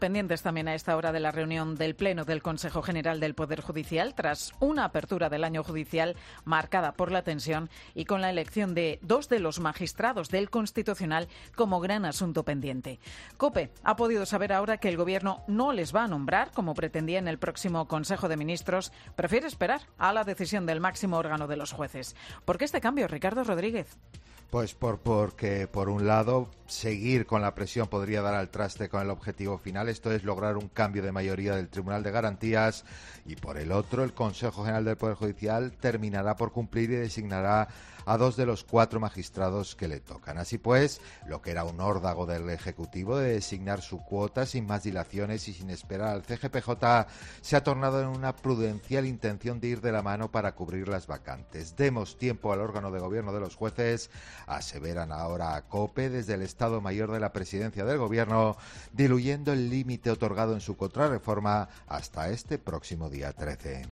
redactor de COPE, da la última hora de la decisión del Gobierno respecto al CGPJ